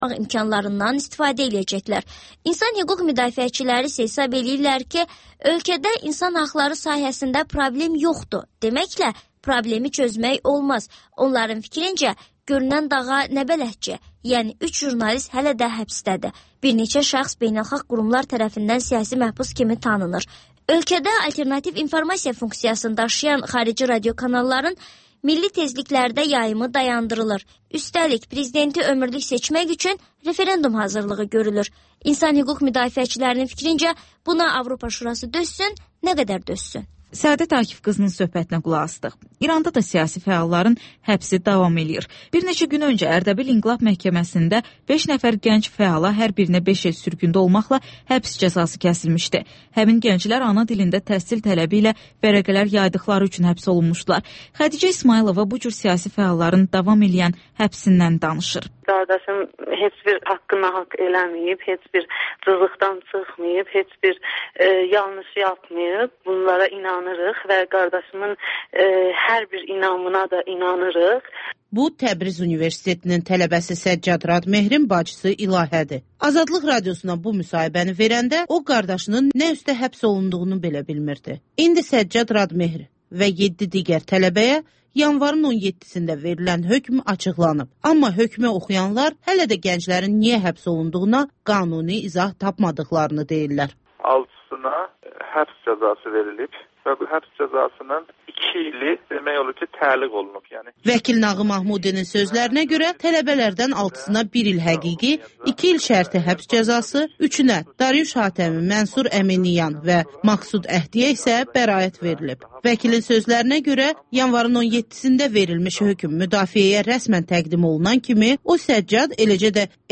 Ölkənin tanınmış simaları ilə söhbət (Təkrar)